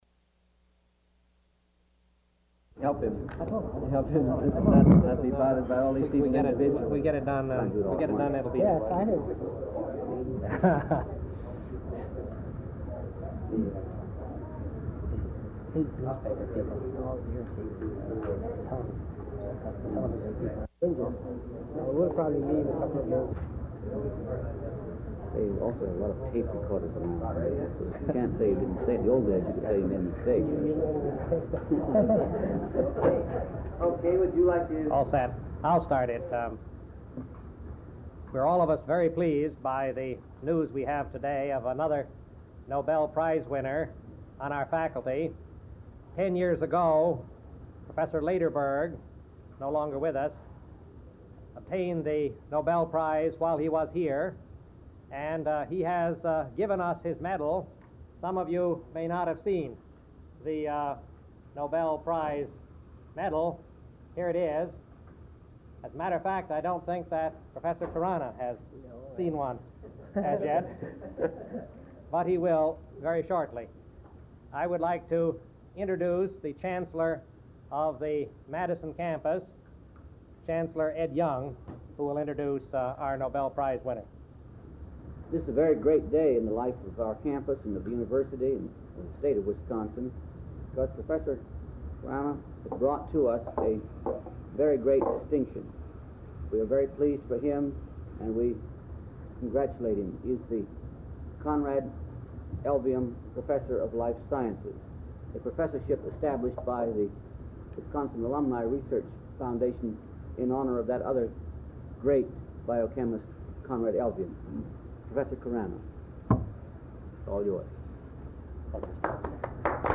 Press Conference: H. Gobind Khorana